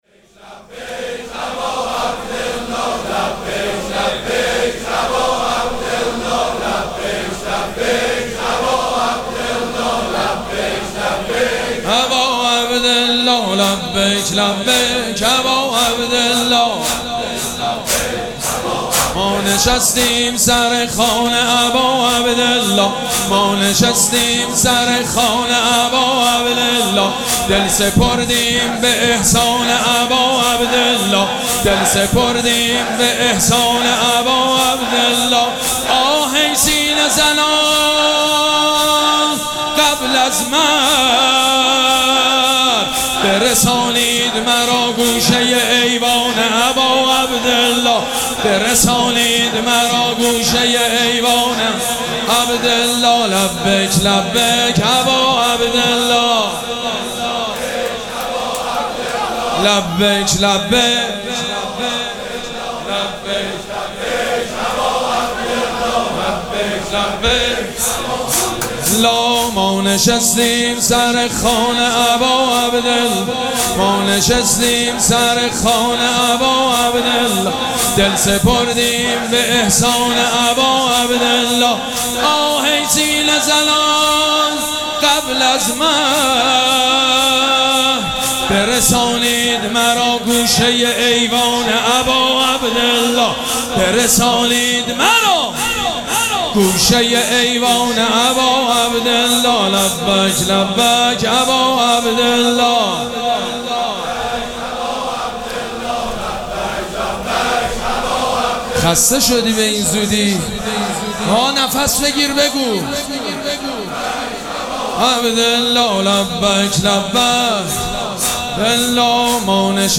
مراسم عزاداری شب پنجم محرم الحرام ۱۴۴۷
مداح
حاج سید مجید بنی فاطمه